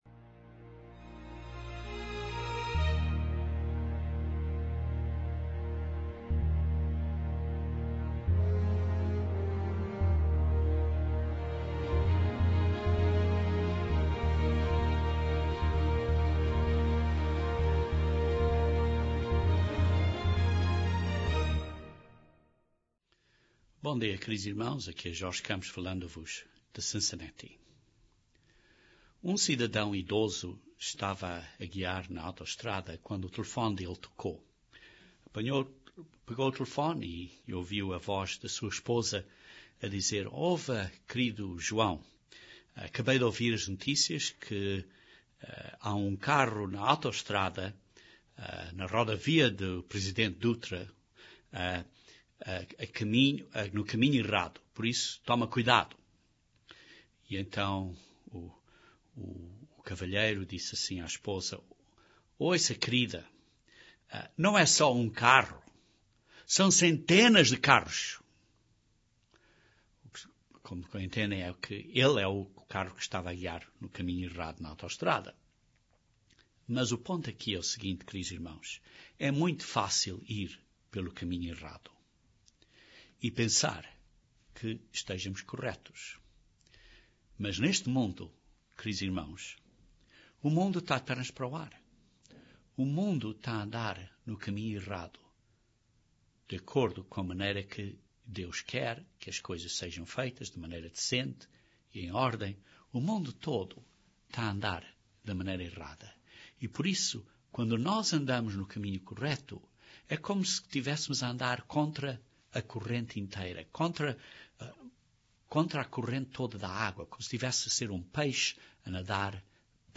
Este sermão estuda, com uma mente aberta, a pura verdade das escrituras acerca de quem Jesus Cristo é, e consequentemente o que viremos a ser.